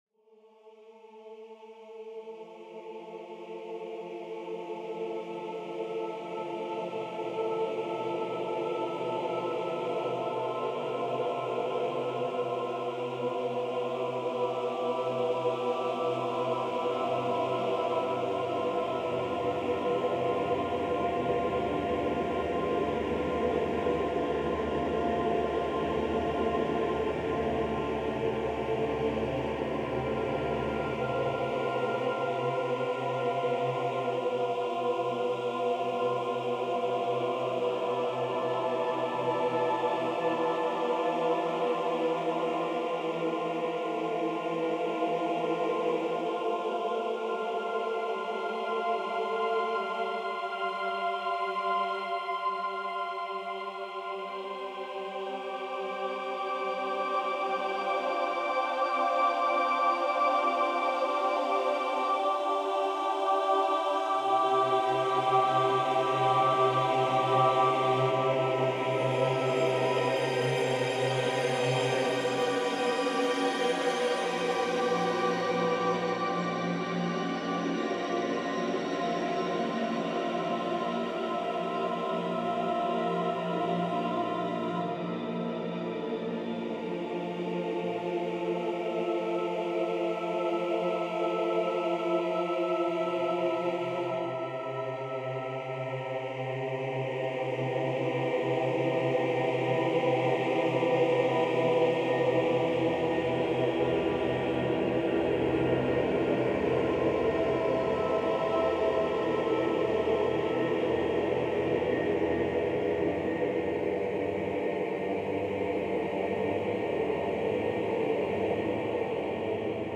Below are a couple of soundscapes that include a significant amount of Nodal generated sequence.
• The style and instrumentation acknowledges “Lux Aeterna” – written for 16-part mixed choir, by György Ligeti in 1966.